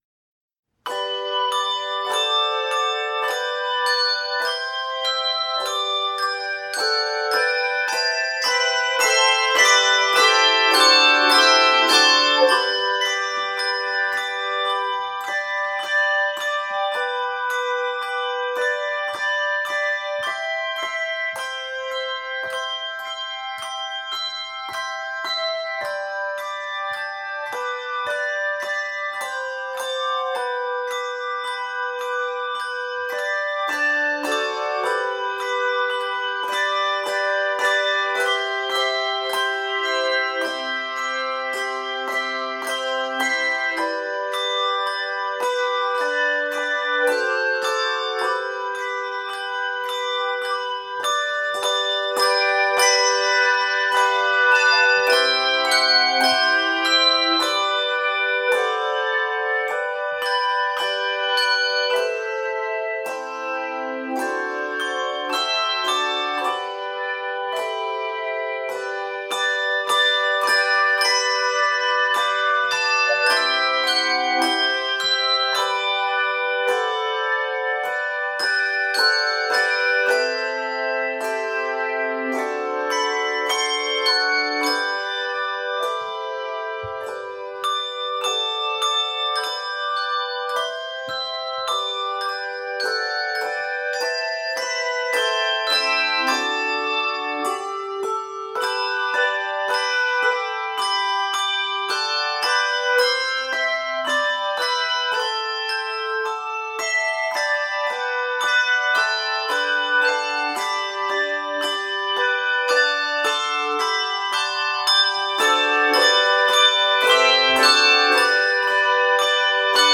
Chimes or Bells
medley
The LV and shake techniques are used.